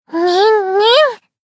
贡献 ） 分类:蔚蓝档案语音 协议:Copyright 您不可以覆盖此文件。
BA_V_Tsukuyo_Battle_Shout_3.ogg